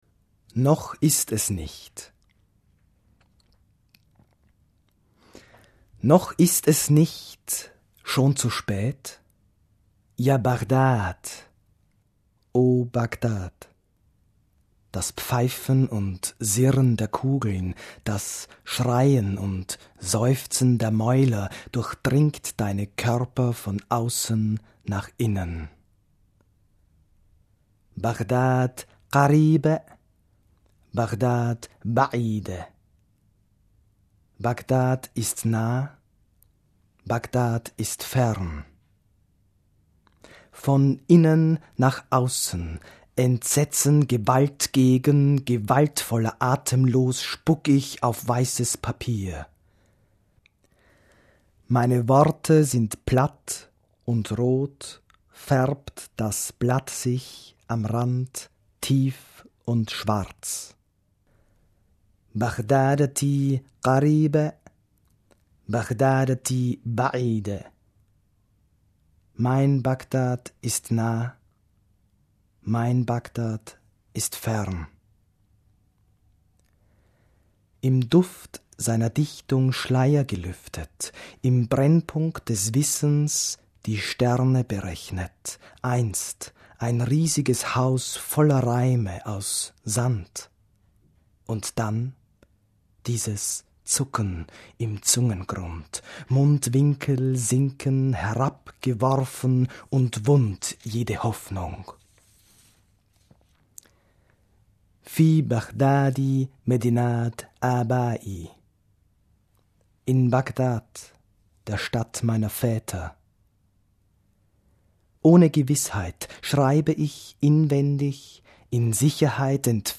Gelesen vom Autor.